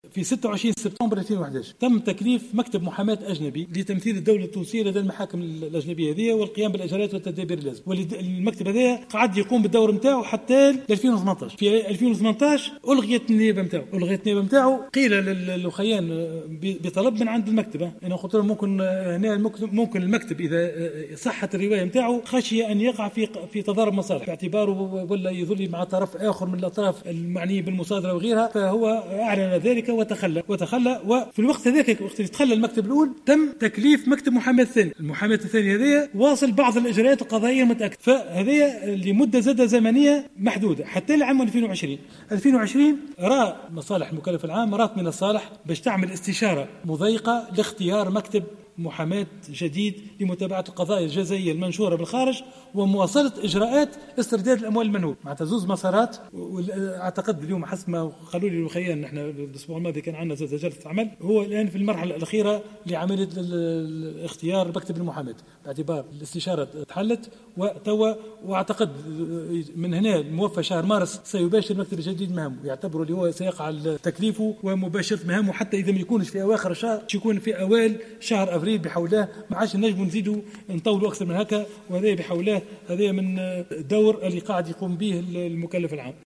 بيّن وزير أملاك الدولة والشؤون العقارية بالنيابة، أحمد عظوم، في رده على اسئلة النواب خلال جلسة عامة اليوم ، بخصوص ملف استرجاع الأموال المنهوبة بالخارج من الرئيس السابق زين العابدين بن علي وعائلته ورموز نظامه، أنه تم تكليف مكتب محاماة أجنبي في شهر سبتمبر 2011 وألغيت نيابته سنة 2018 وتلقى أجرة بمبلغ قدره 200 ألف أورو.